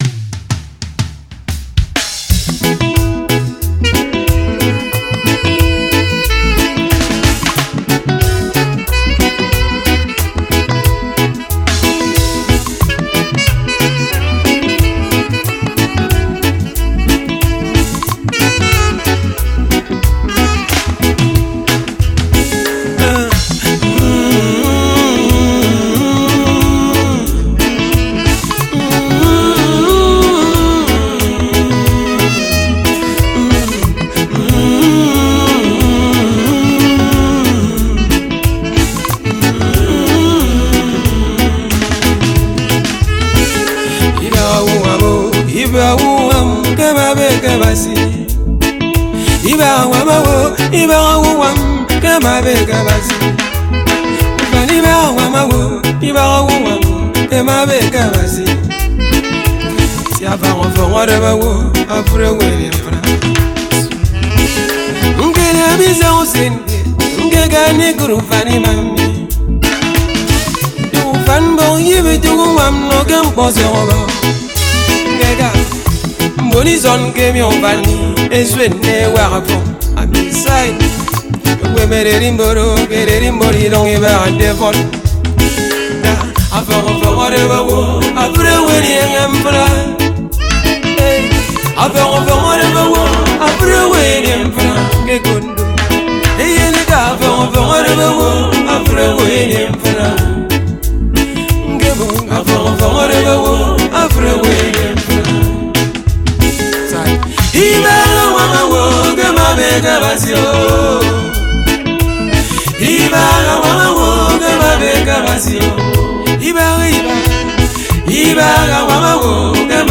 February 28, 2025 Publisher 01 Gospel 0